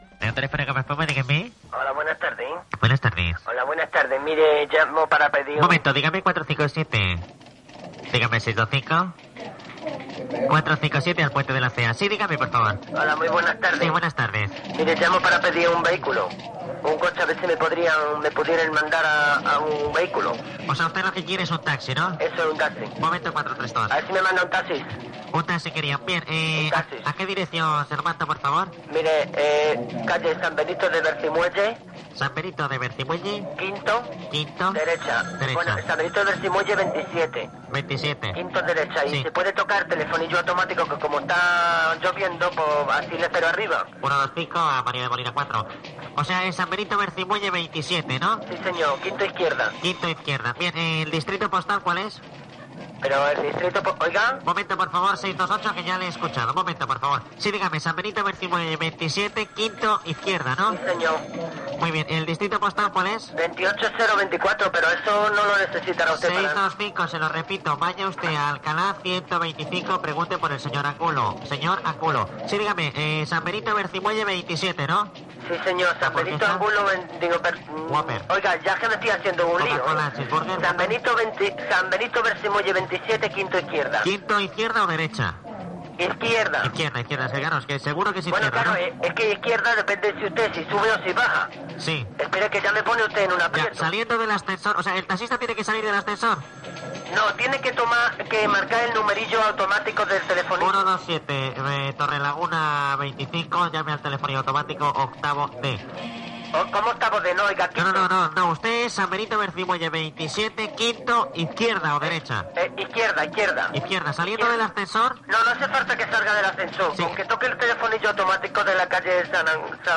"Sketch" del servei de radiotelèfon de Gomaespuma.
Entreteniment